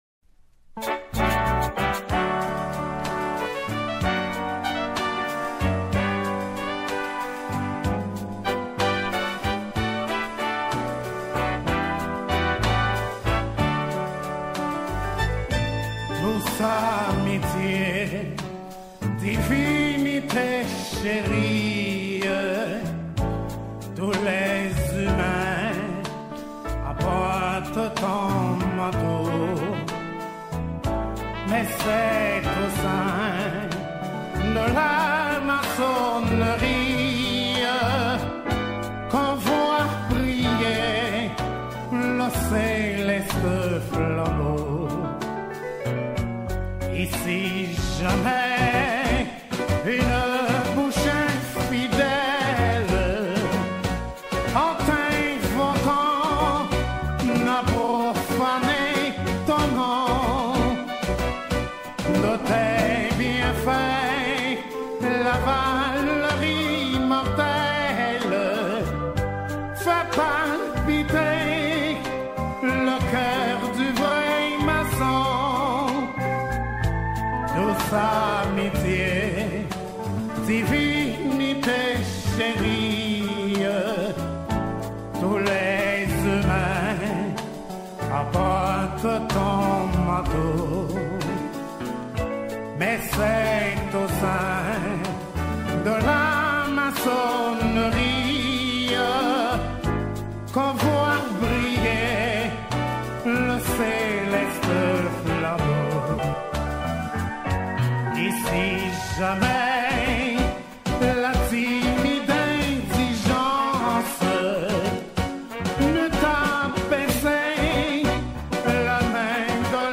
2022 CHANTS MYSTIQUES audio closed https